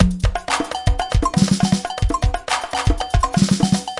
循环 " 桑巴
描述：桑巴的Percursive音频循环
Tag: 快乐 巴西 桑巴 percurssion